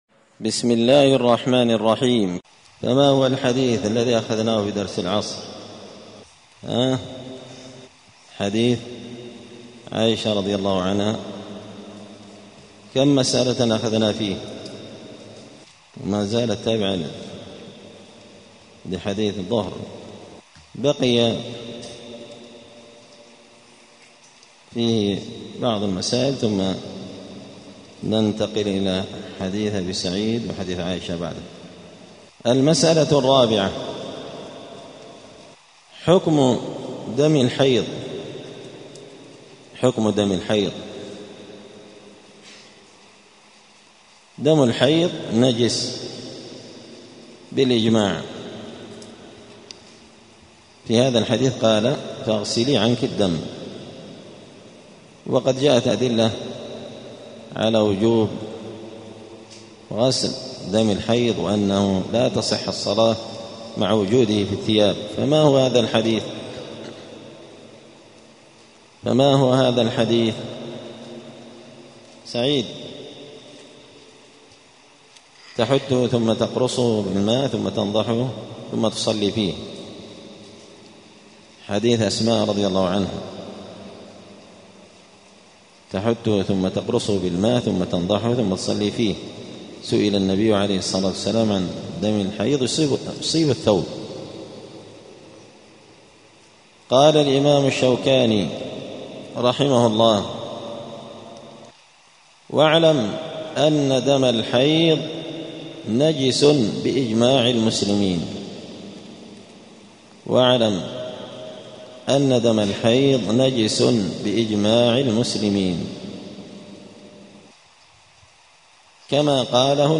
دار الحديث السلفية بمسجد الفرقان قشن المهرة اليمن
*الدرس التاسع والتسعون [99] {باب الحيض حكم دم الحيض}*